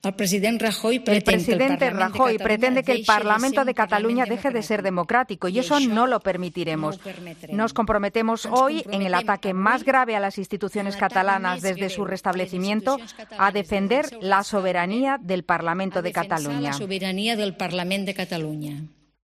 En una declaración institucional en la cámara catalana tras las medidas acordadas hoy por el Gobierno al amparo del 155 de la Constitución y para restaurar la legalidad en Cataluña, Forcadell ha opinado que en las medidas acordadas en el Consejo de Ministros "no les acompañan ni la legalidad, ni la legitimidad política", ni cuentan "con el apoyo de una mayoría de los catalanes".